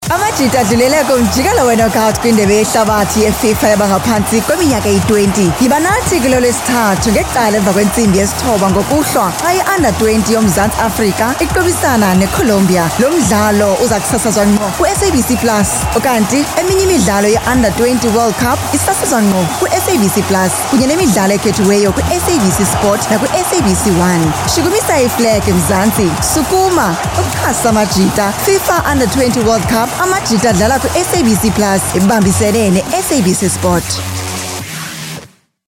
articulate, authoritative, bright, commercial, confident, conversational, energetic, informative
Her voice has a unique or rare natural warmth, that can capture the attention of the audience.
English Hard Sell Sport